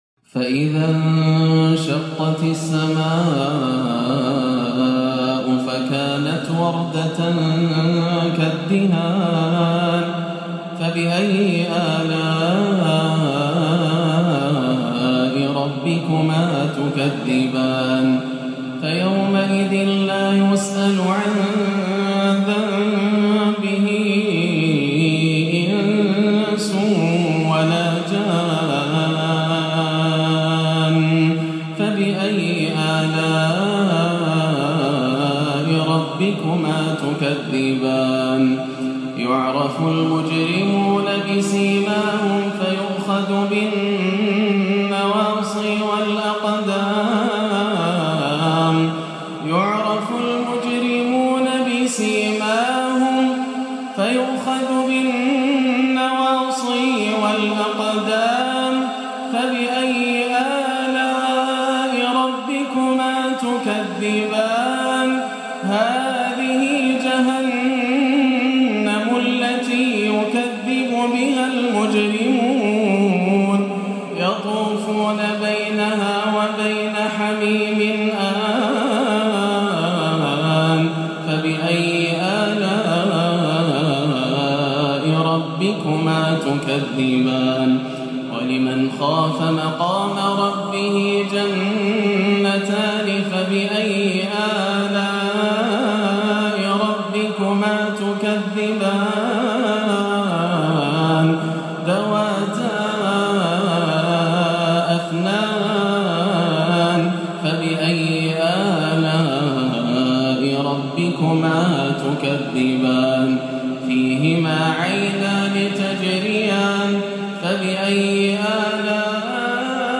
وصف الجنة بالبنجكا الآسره والصبا الحزين كانت هكذا عشائية شيخنا الغالي - الأحد 18-11 > عام 1437 > الفروض - تلاوات ياسر الدوسري